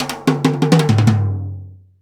Index of /90_sSampleCDs/Roland L-CD701/TOM_Rolls & FX/TOM_Tom Rolls
TOM TOM R01R.wav